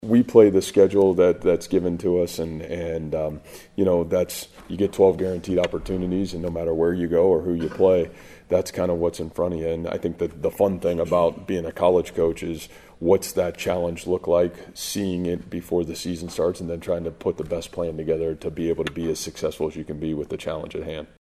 That's ISU coach Matt Campbell, who does not believe the Cyclones will take the Red Wolves for granted.